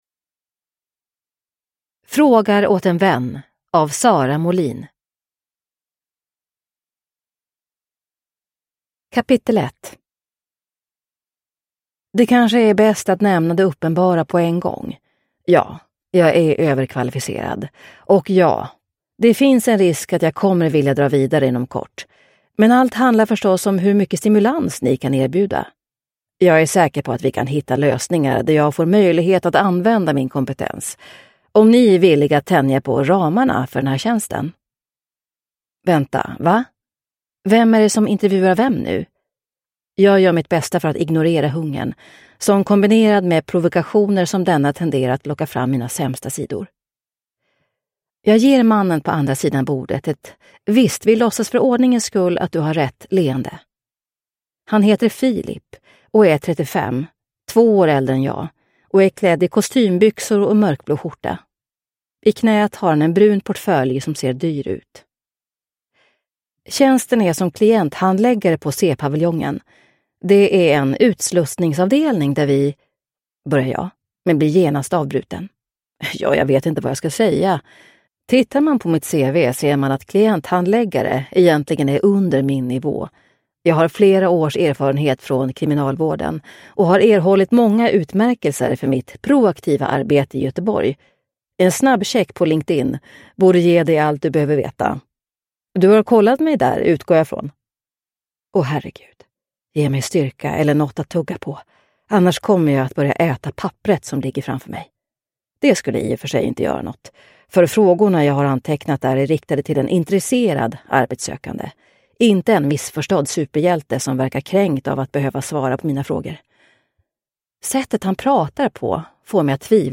Frågar åt en vän – Ljudbok – Laddas ner